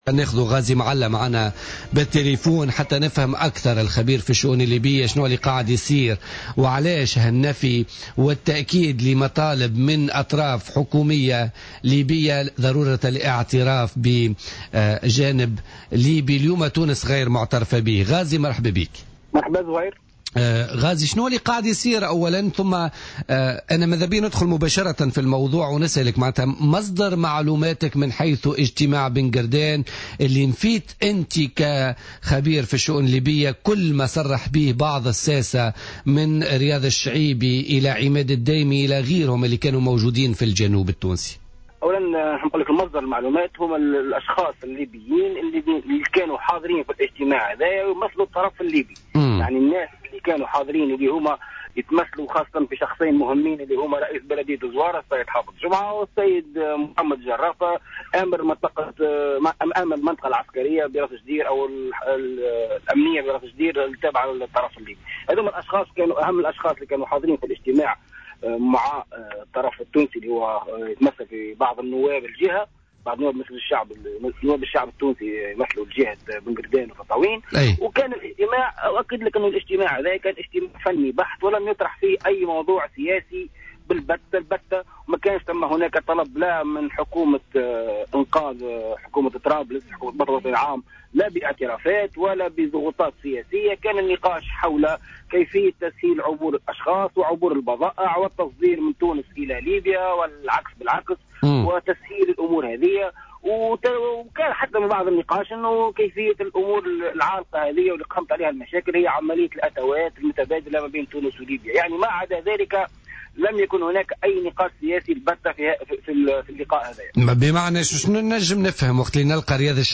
مداخلة له اليوم في برنامج "بوليتيكا"